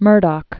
(mûrdŏk), Dame (Jean) Iris 1919-1999.